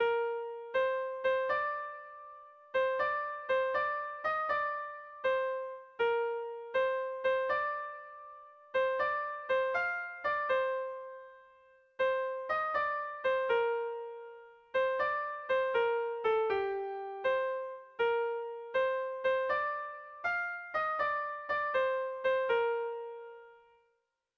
Erromantzea
Laukoa, berdinaren moldekoa, 4 puntuz (hg) / Lau puntukoa, berdinaren moldekoa (ip)
AABA2